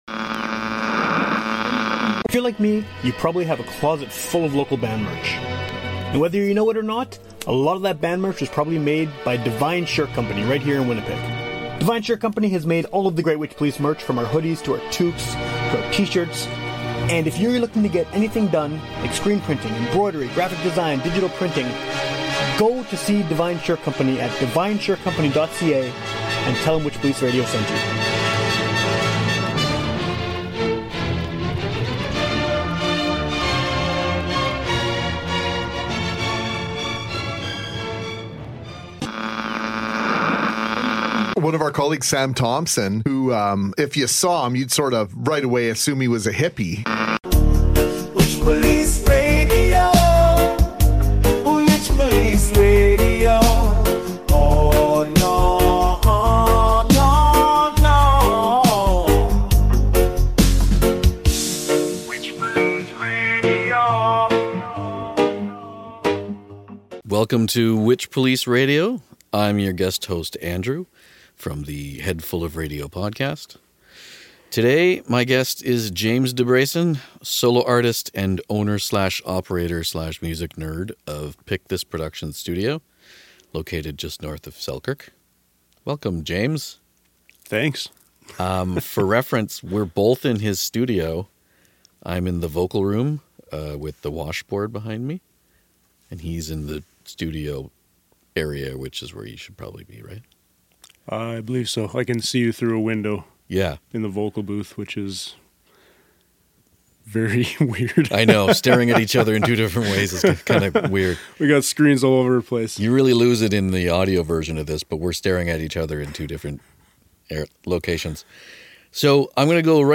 Another guest-hosted episode!